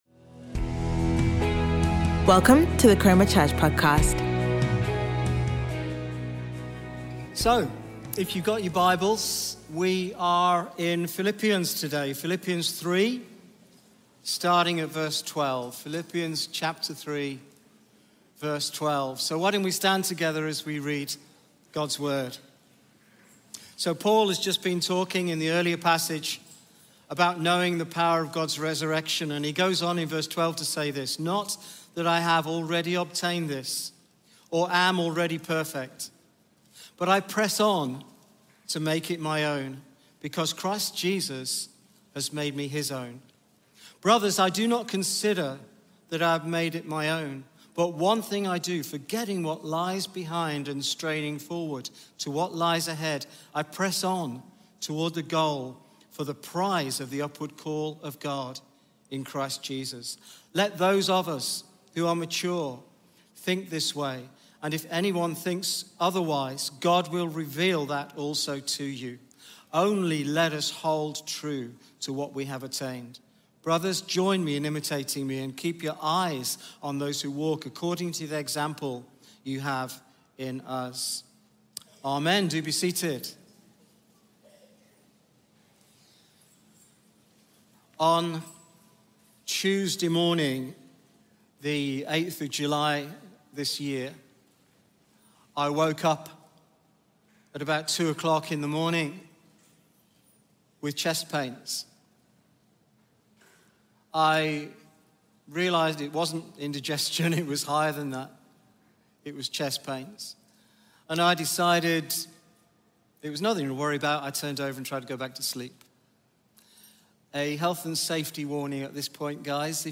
Sunday Sermon
Chroma Church Live Stream